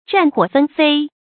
战火纷飞 zhàn huǒ fēn fēi
战火纷飞发音